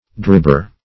Search Result for " dribber" : The Collaborative International Dictionary of English v.0.48: Dribber \Drib"ber\, n. One who dribs; one who shoots weakly or badly.
dribber.mp3